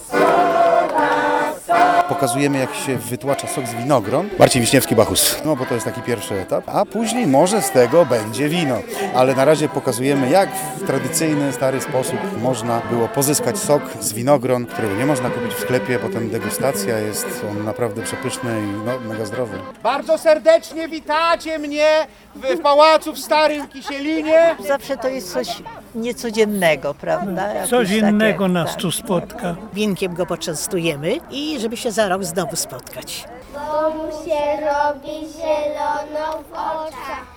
Bóg wina od początku Winobrania przemierza sołectwa dzielnicy Nowe Miasto. Dziś wraz z grupą Akademia Tradycji pokazywał przed pałacem w Starym Kisielinie jak wyciska się sok z winogron: